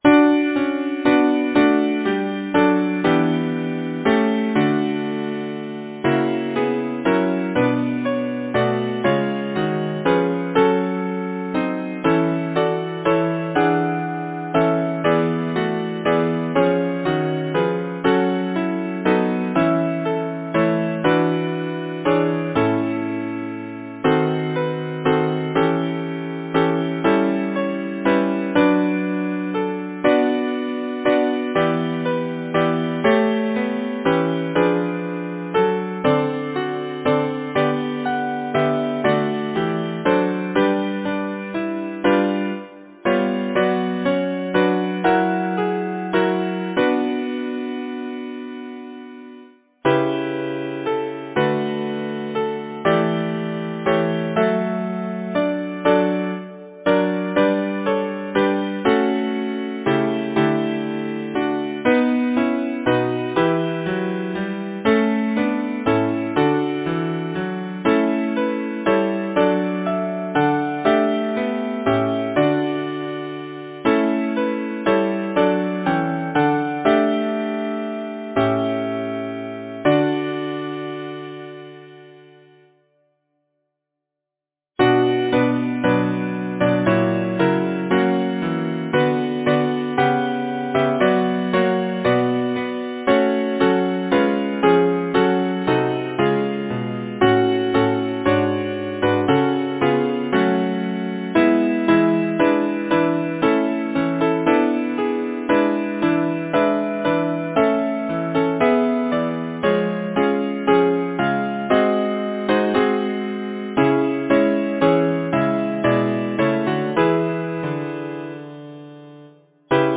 Title: Spring’s welcome Composer: Clowes Bayley Lyricist: Number of voices: 4vv Voicing: SATB Genre: Secular, Partsong
Language: English Instruments: A cappella